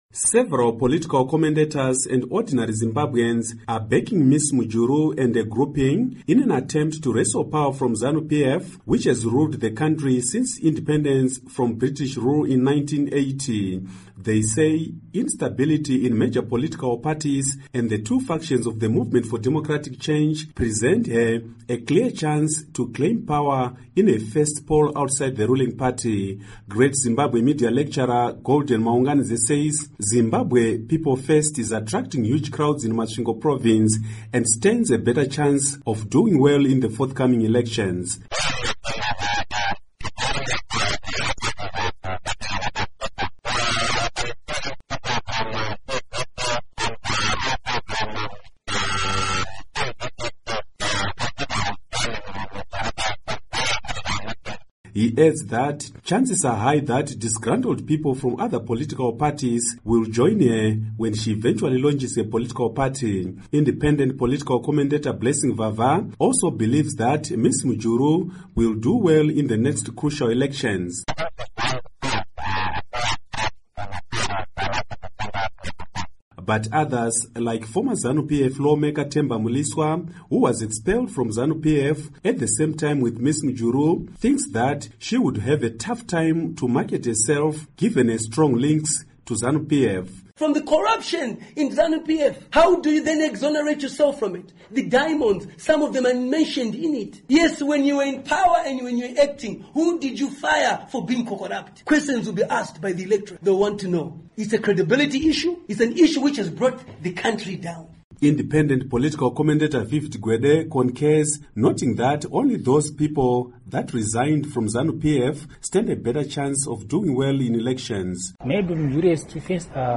Report on Former VP Mujuru